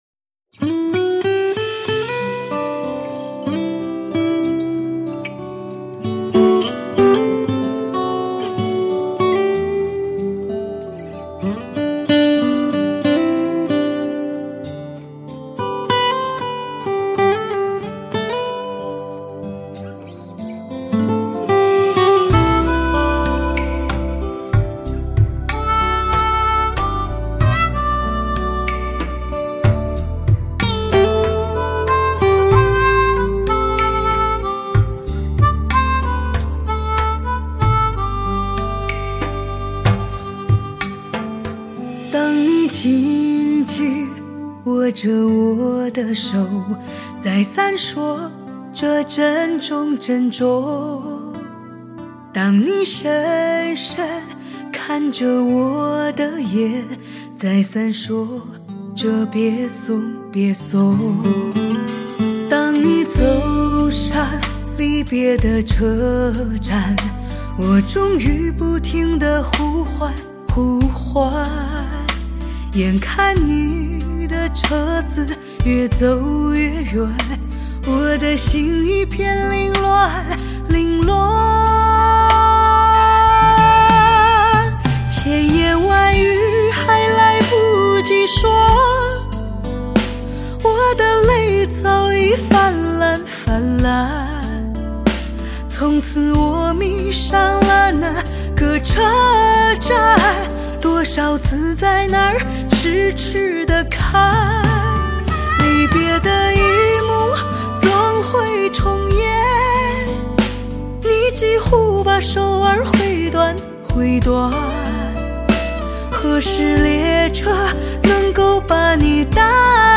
引领潮流的发烧试音典范
绝佳的录音技术不容置疑 极具声色感染力的发烧音效